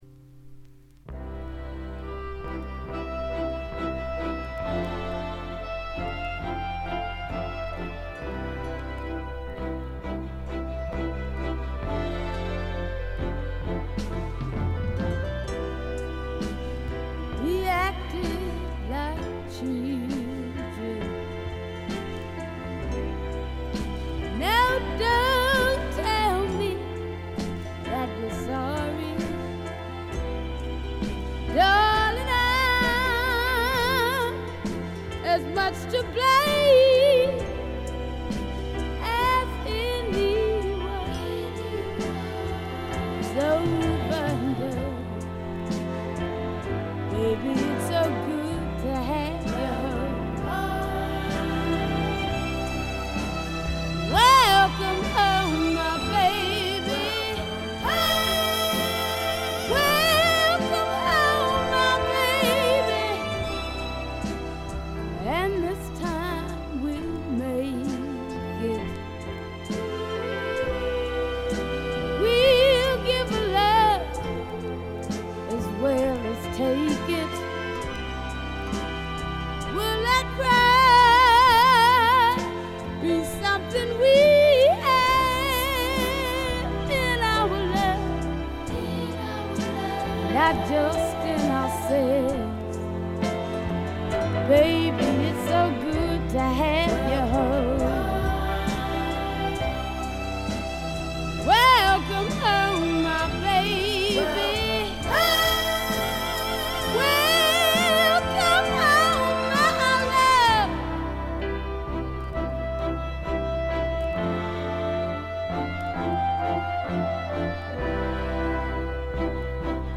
全体にバックグラウンドノイズ、チリプチ多め。
試聴曲は現品からの取り込み音源です。